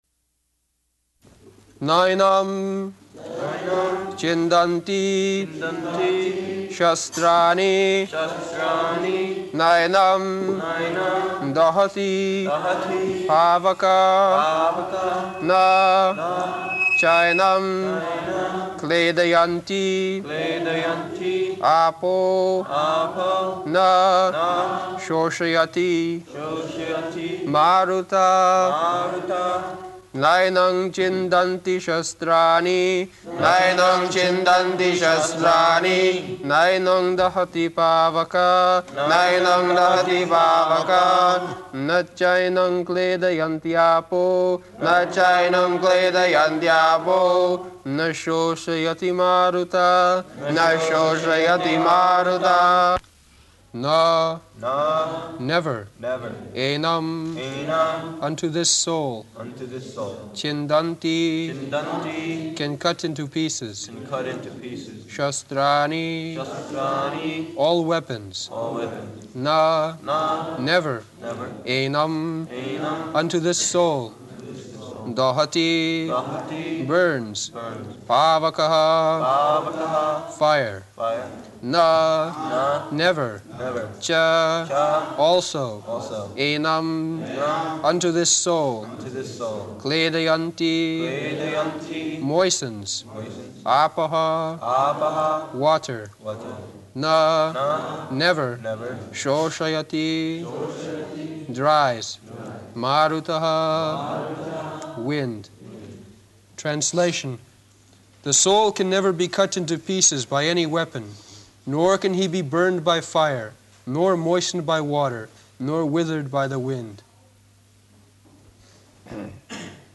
Location: London
[leads chanting of verse] [devotees repeat]